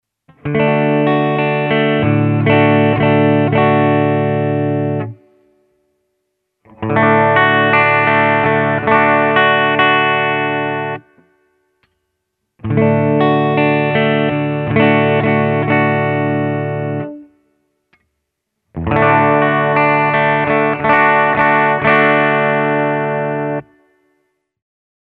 The closer the unit is moved towards the bridge, the brighter and sharper the sound gets. The closer you move to the end of the fretboard, the warmer and fuller the sound becomes.
Here I’m switching from the neck pickup to the bridge pickup and back again:
Neck-Bridge-Neck-Bridge
hamer-neck-then-bridge.mp3